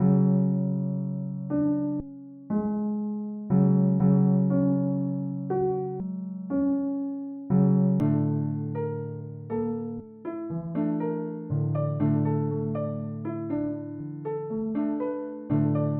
我所做的就是按住C键和毛拍。
Tag: 120 bpm Trap Loops Pad Loops 2.69 MB wav Key : C FL Studio